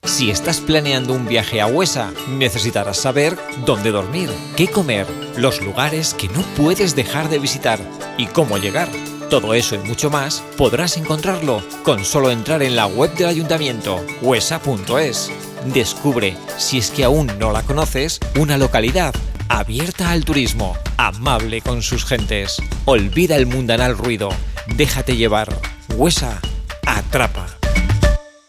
CUÑA DE RADIO PARA FITUR 2018 EN MADRID